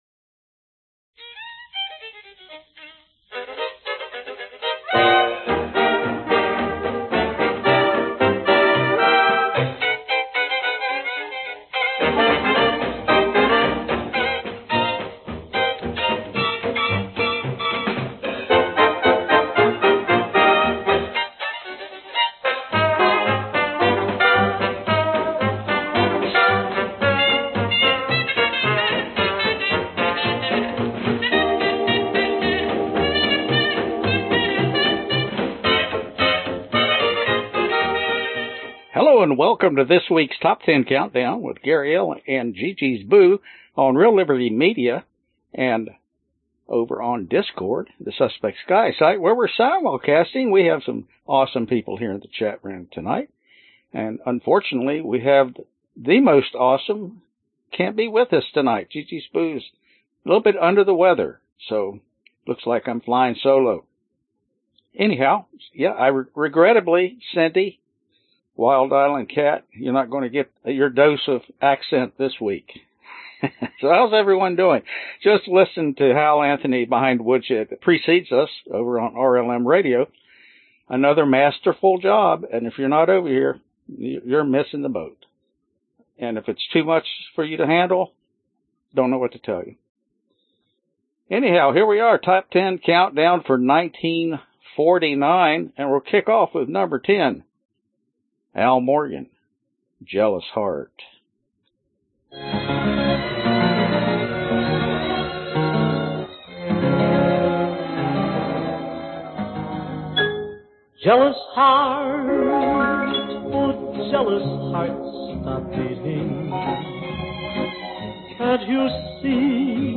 1948 Genre Oldies Year 2021 Duration 54:46